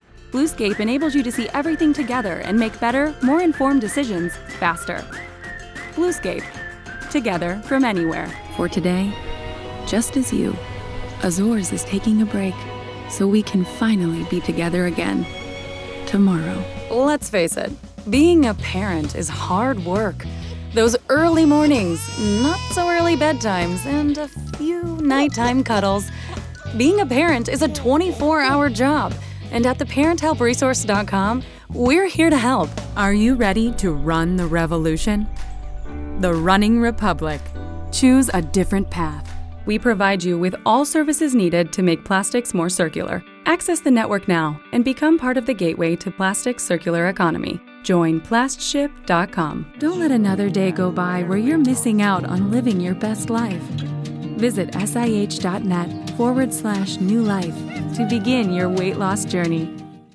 Commercial Demo
English (North American), German